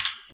AssMilk_Perc.wav